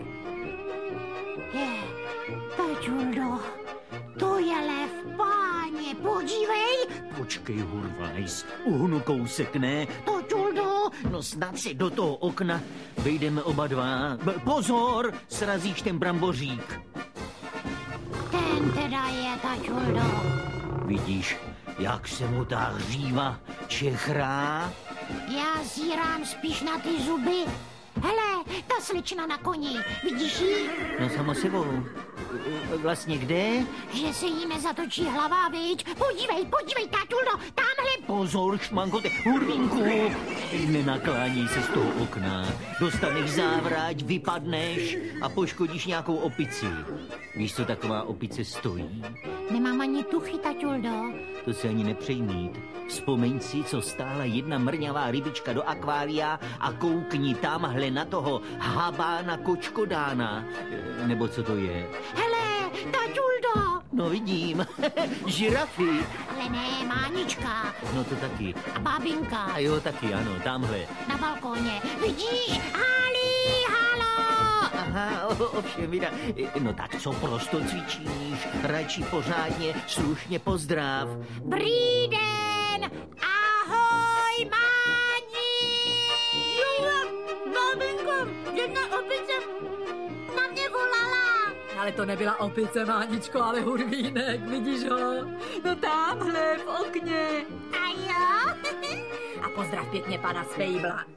Oblíbená a úspěšná řada archivních nahrávek Divadla Spejbla a Hurvínka s Milošem Kirschnerem, dlouholetým interpretem obou dřevěných protagonistů a ředitelem tohoto divadla, pokračuje už čtrnáctým titulem
Audio kniha